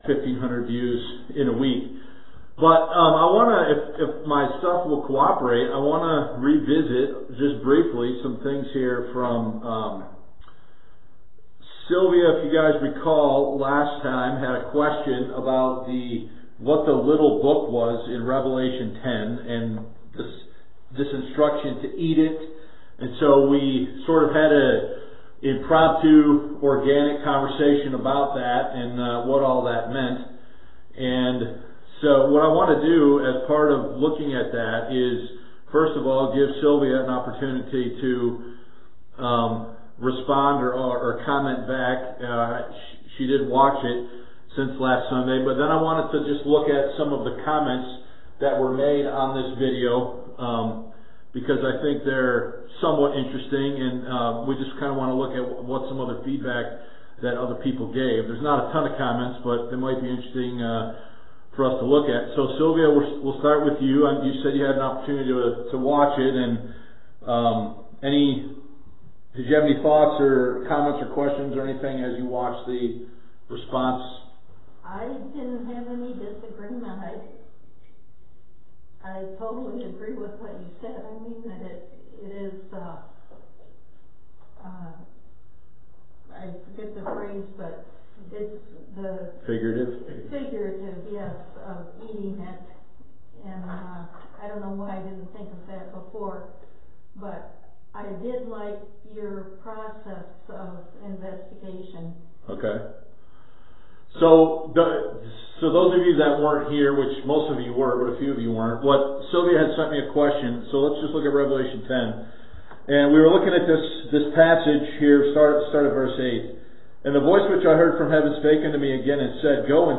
Adult Sunday School Q&A: “Little Book” (Rev. 10), “Of Purpose” (Ruth 2:16), “Brigandine” (Jer. 51:3)